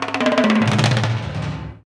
Index of /90_sSampleCDs/Roland - Rhythm Section/TOM_Rolls & FX/TOM_Tom Rolls
TOM TOM R08L.wav